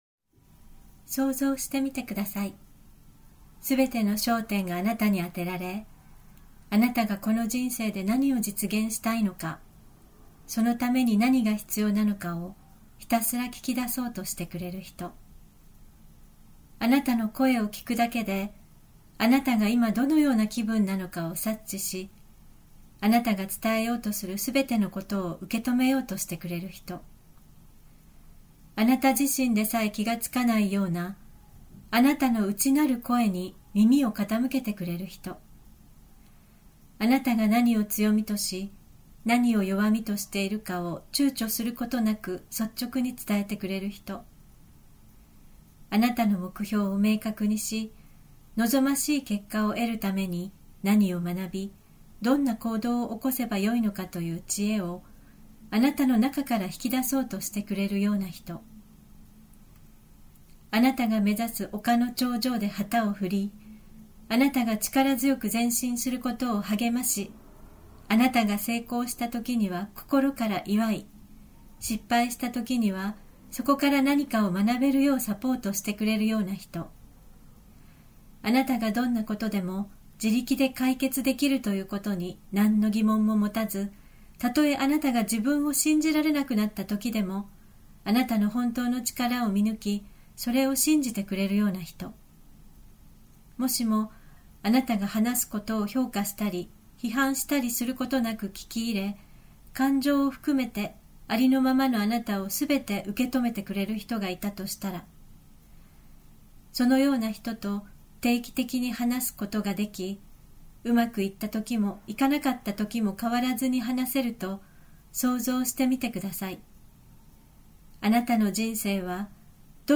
コーチからのメッセージ